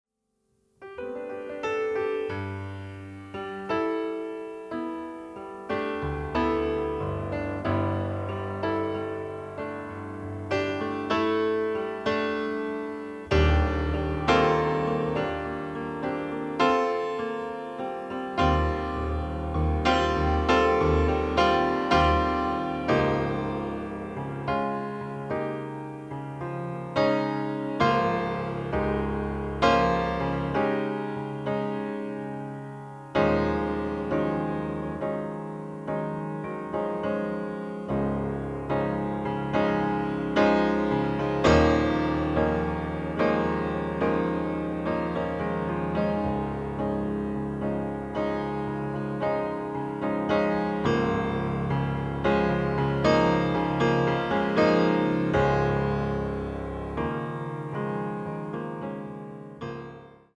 piano, fender rhodes, synthesizers